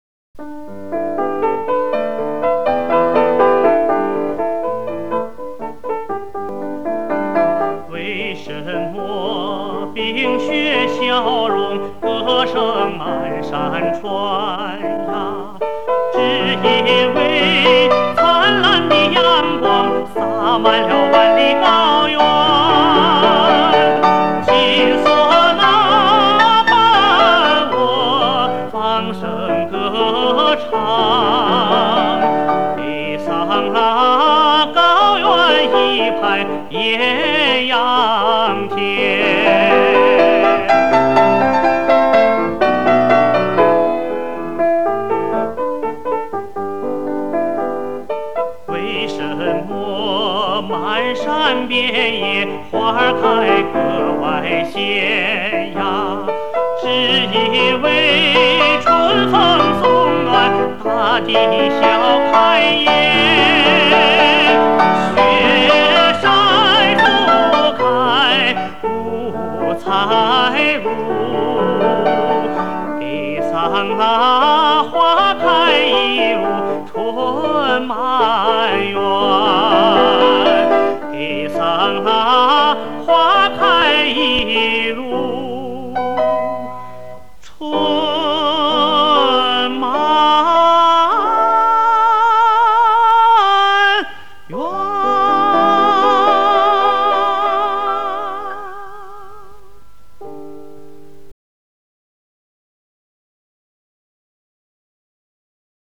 资料类型：磁带转WAVE